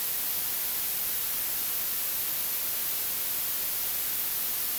Dither
While today’s noize isn’t very musical, It will hopefully be educational.
Typically it lives far below audible levels.